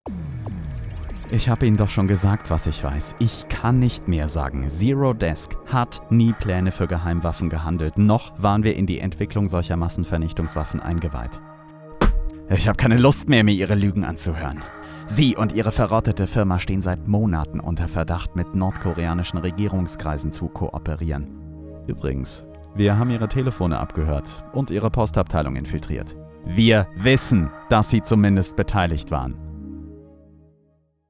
Sprechprobe: Sonstiges (Muttersprache):
Professional Speaker for News, OFF, E-Learning, Industrial and more...